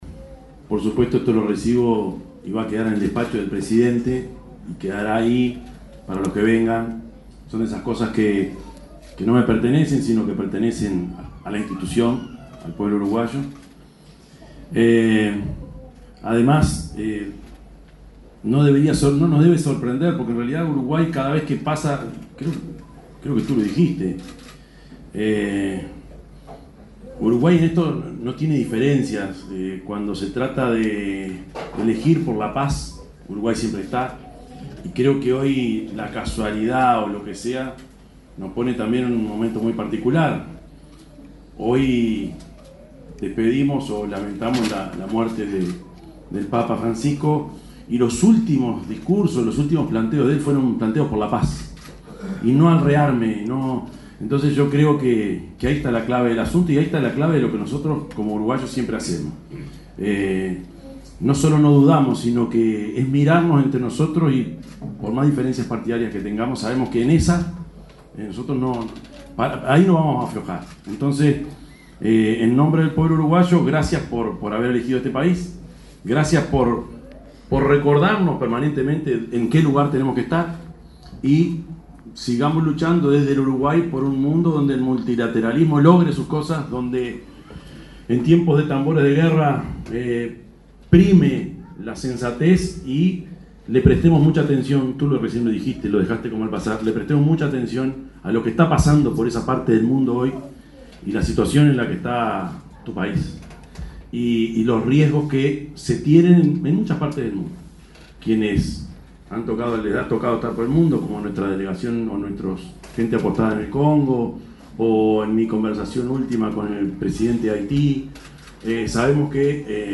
Palabras del presidente de la República, Yamandú Orsi
En el marco de la conmemoración de los 110 años del Genocidio Armenio, se expresó el presidente de la República, profesor Yamandú Orsi.